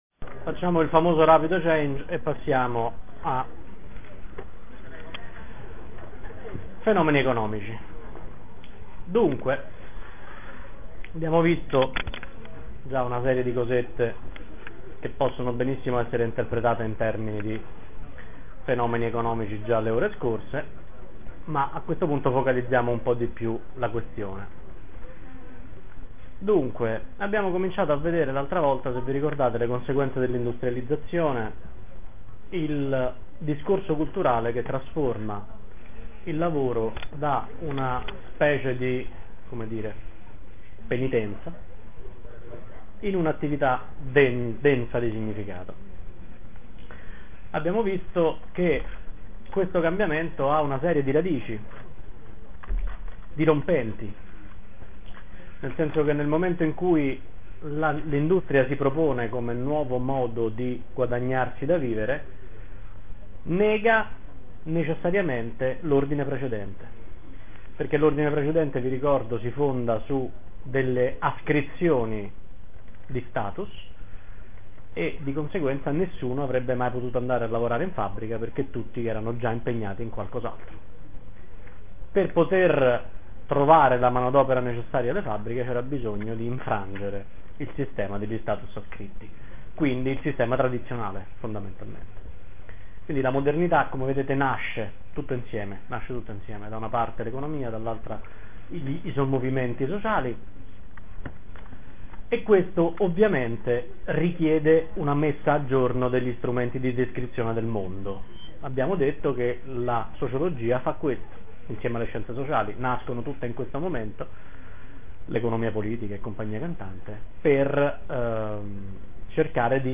Registrazioni delle lezioni